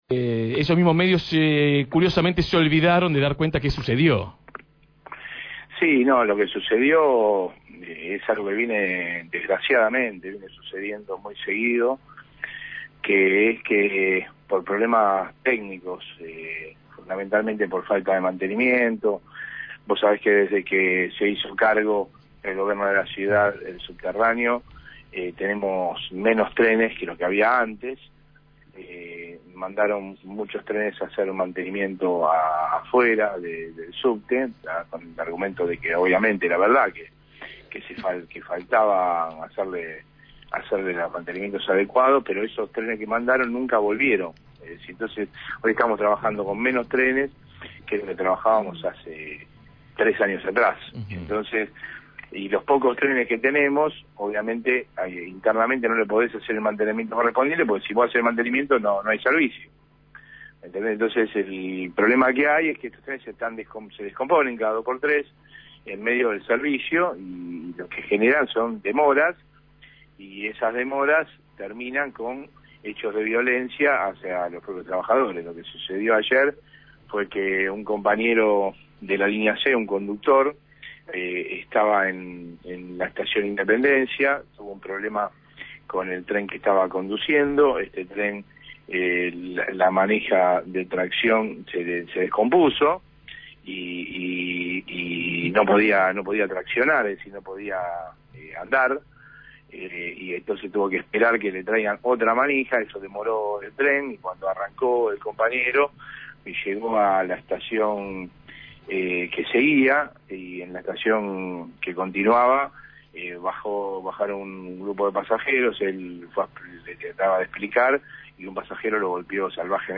en comunicación con Desde el Barrio.
Entrevistado